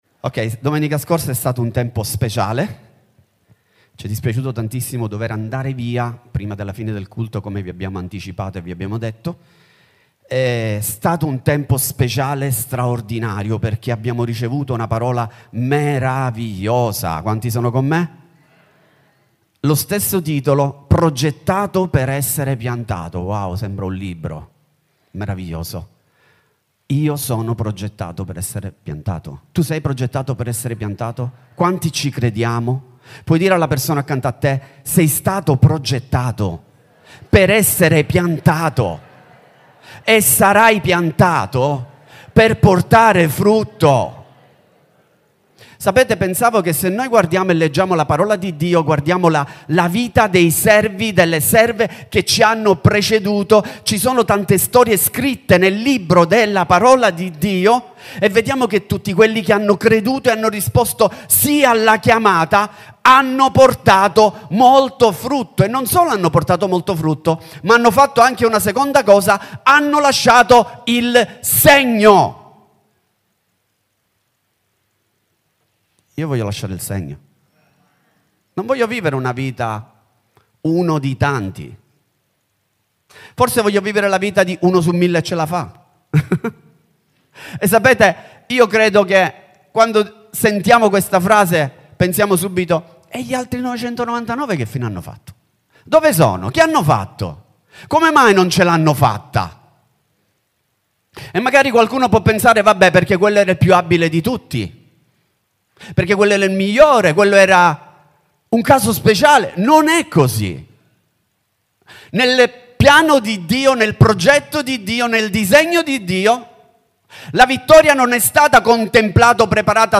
14/09/2025 – Worship service RdM